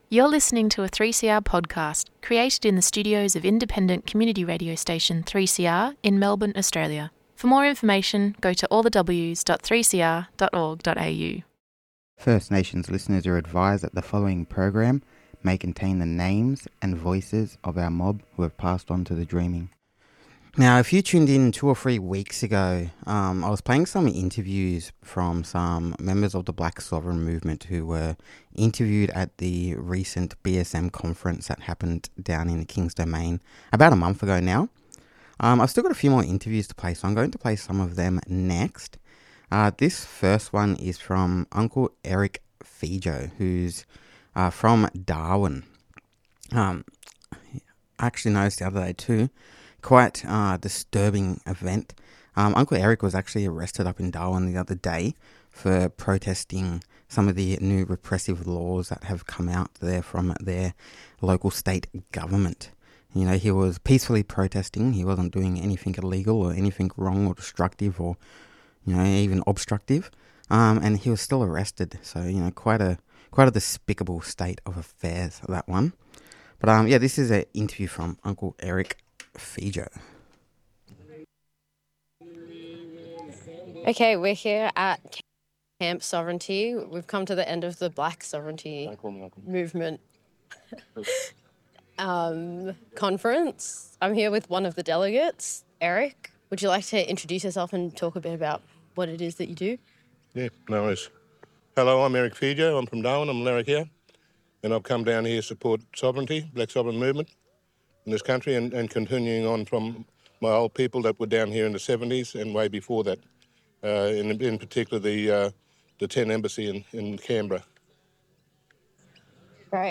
interviews from the blak sovereign movement conference pt. 2